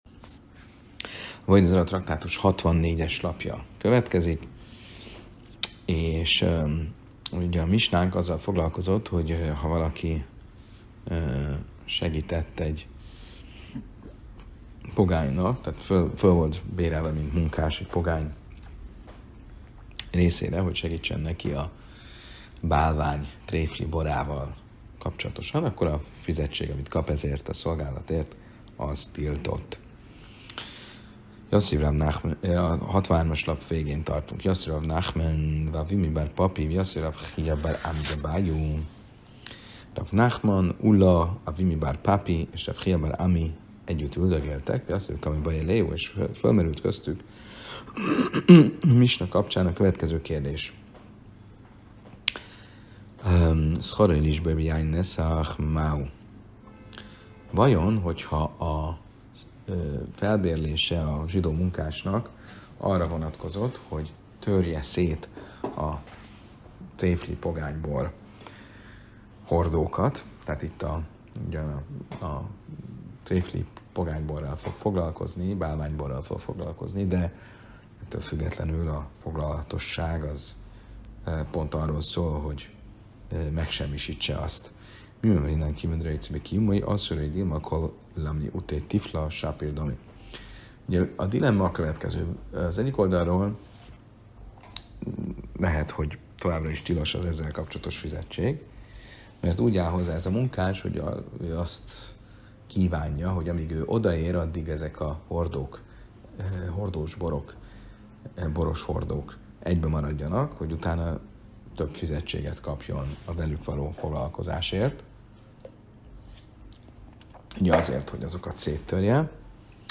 A mai előadás egy új szemszögből közelíti meg a tréfli borral való kapcsolatot: nem fogyasztás vagy szállítás, hanem annak elpusztítása révén.